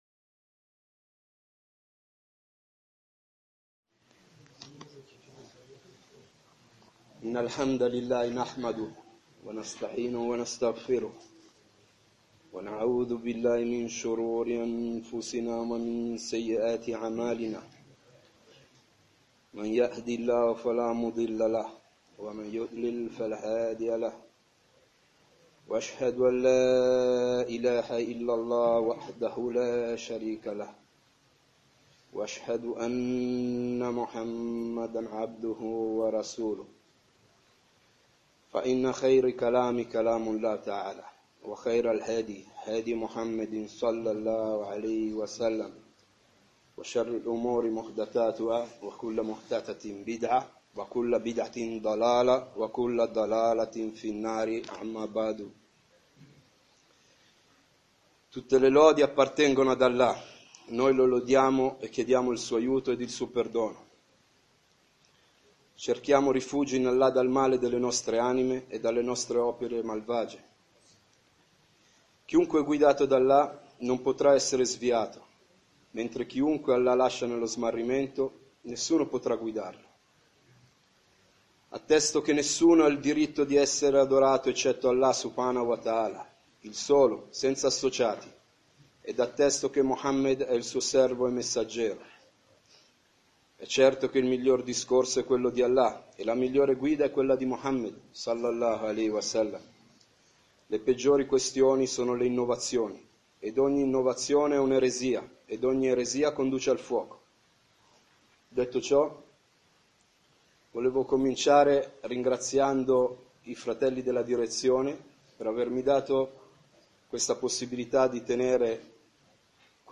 Lezioni